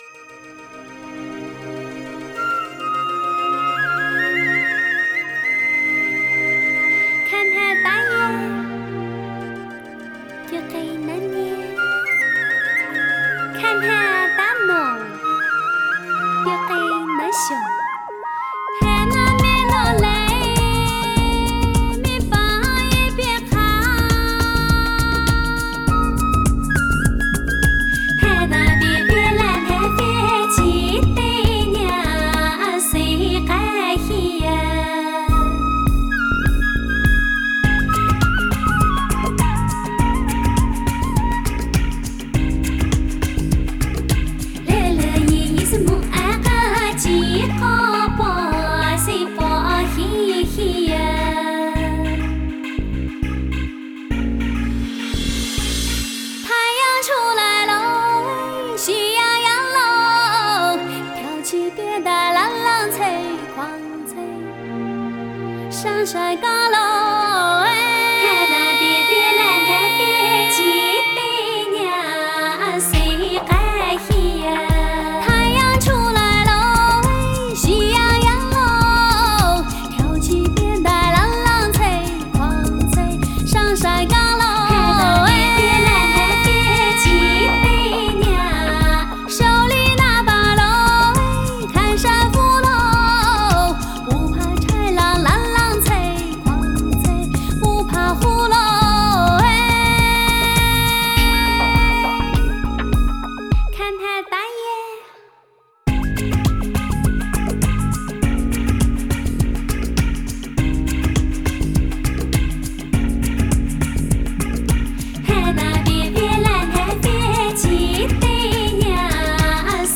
Жанр: Modern Traditional / Cinese pop / Miao folk